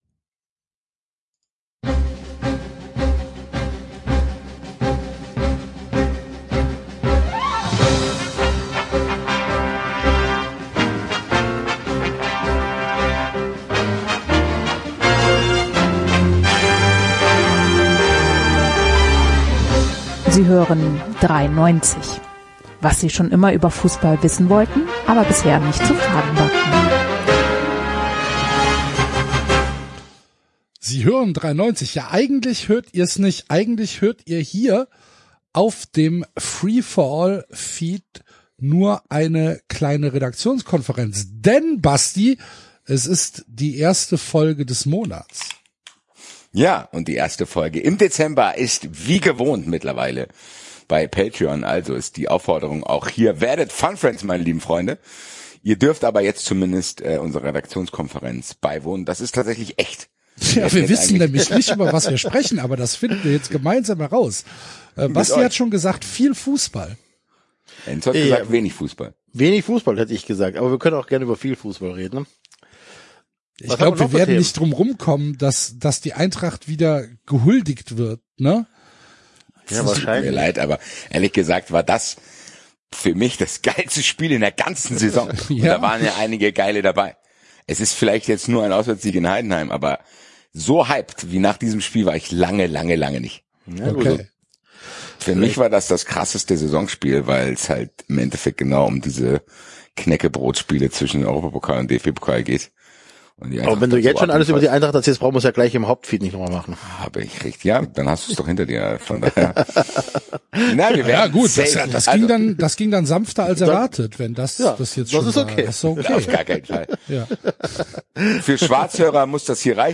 Die erste Folge des Monats gibt es wie immer exklusiv auf Patreon. Aber, damit Ihr wisst, was Ihr verpasst haben wir Euch quasi live unsere Redaktionskonferenz online gestellt.